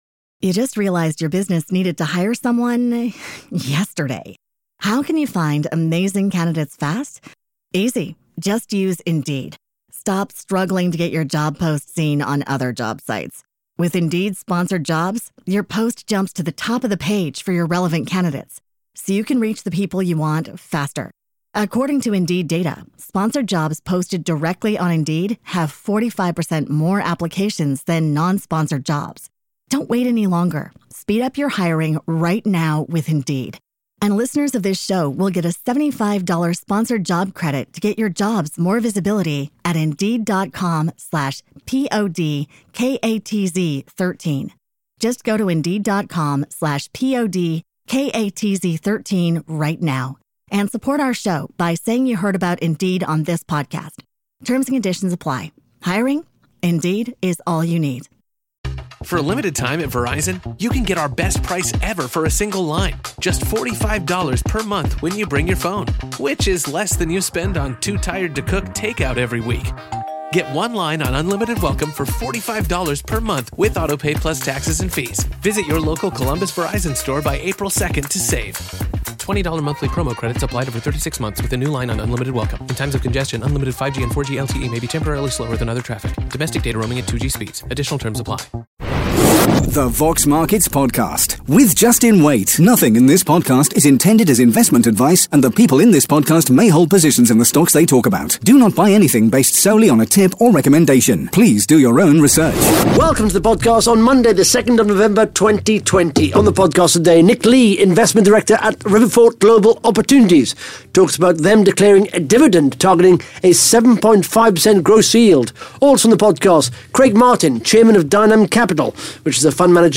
(Interview starts at 5 minutes 35 seconds)